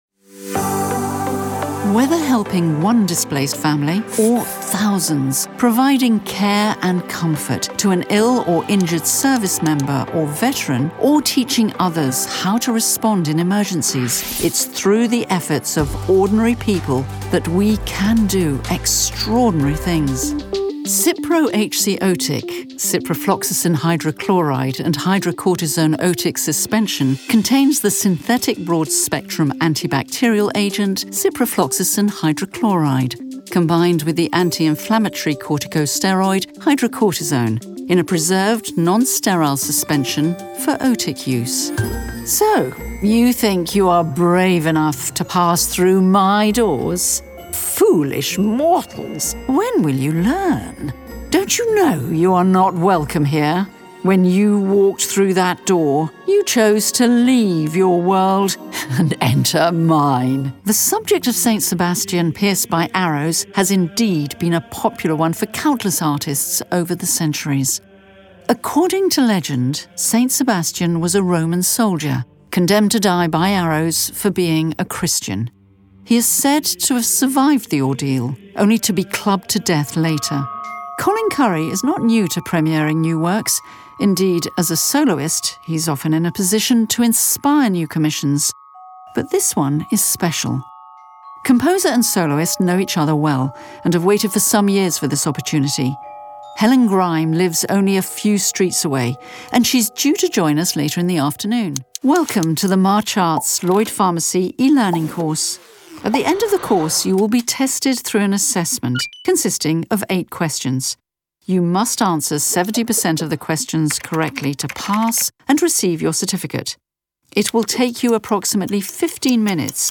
Native British Voice Artist
with Classic British Style
Elegance, Empathy, Warmth and Wisdom
Corporate Demo Reel Audio